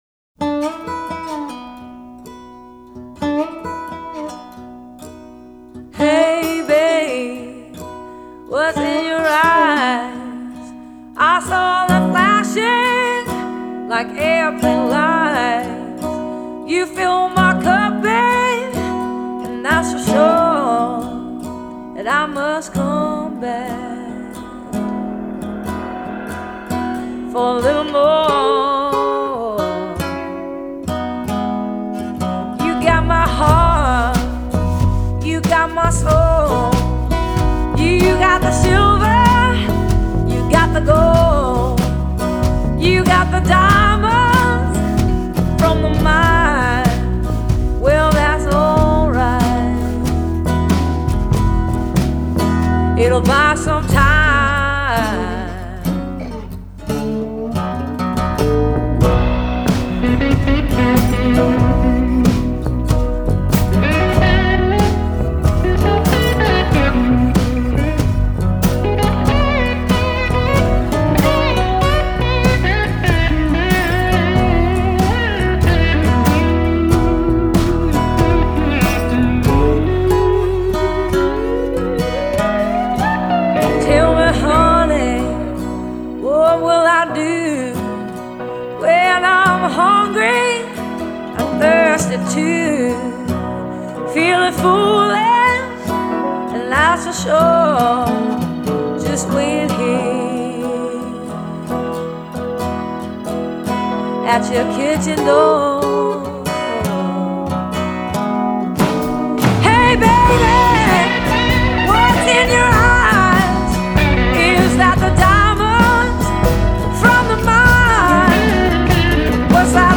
A voice of pain that soothes